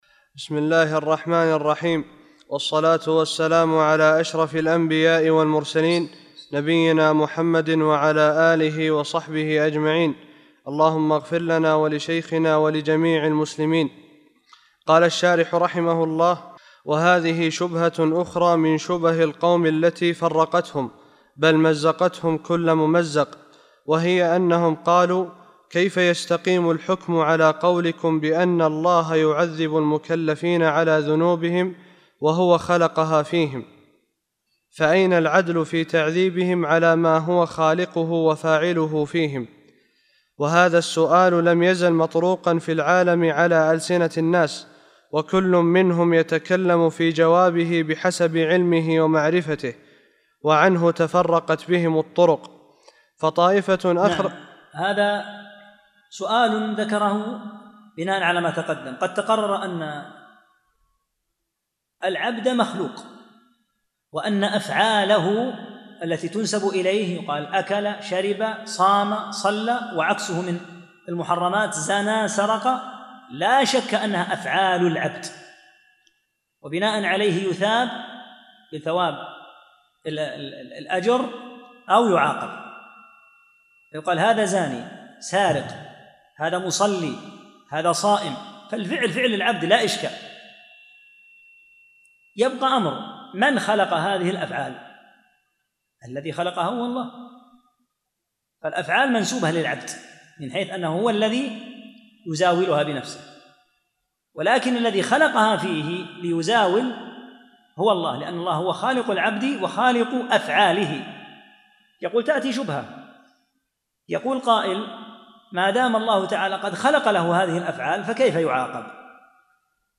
35- الدرس الخامس والثلاثون